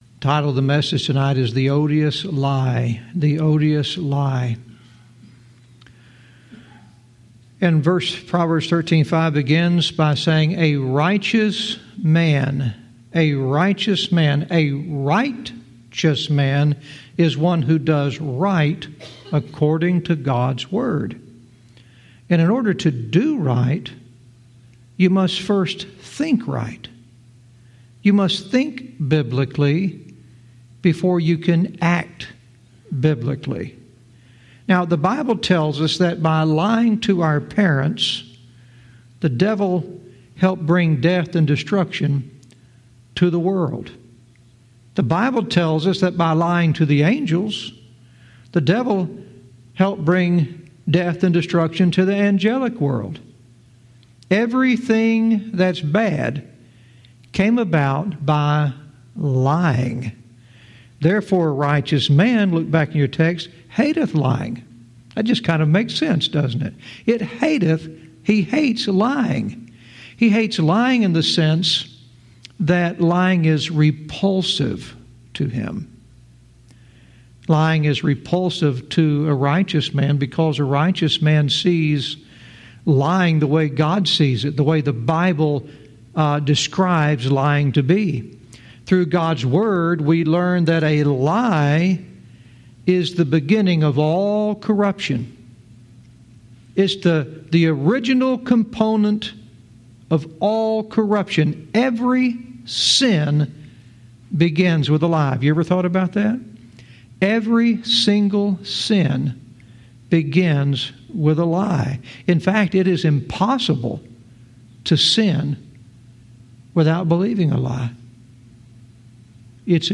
Verse by verse teaching - Proverbs 13:5 "The Odious Lie"